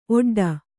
♪ oḍḍa